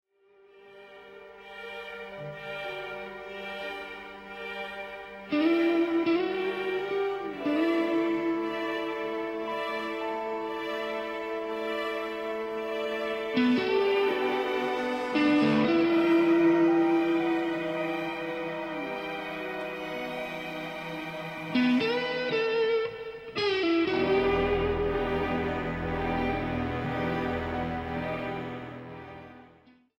Live at Royal Albert Hall, London, England, UK, 1990 - 1991